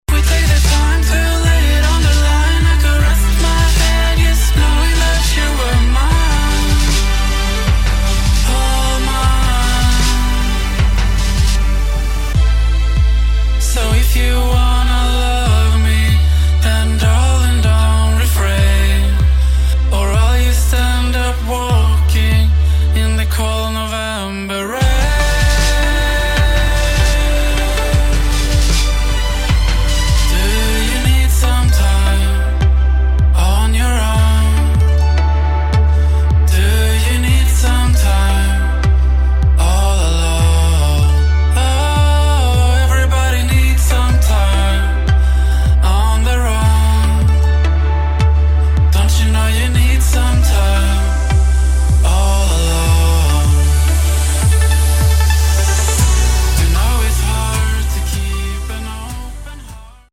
[ POP / ROCK / INDIE ]